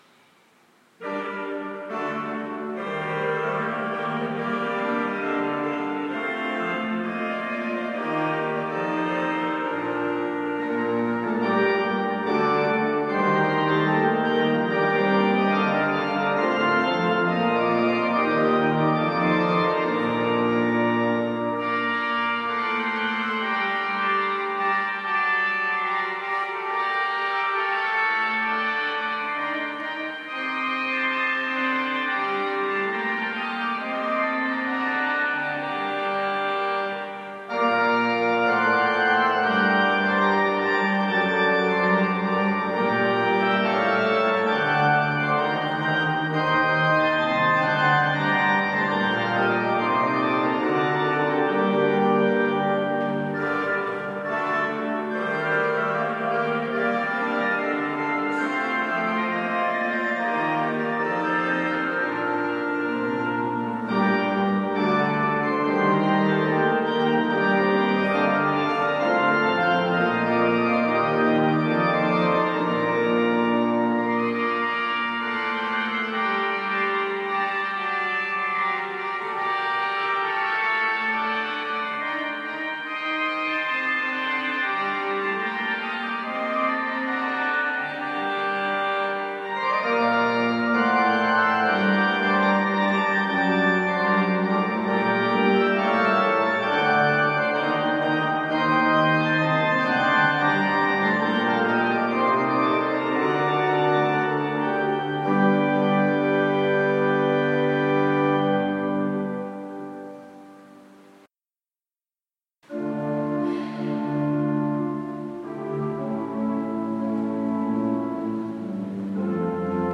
Musiques de films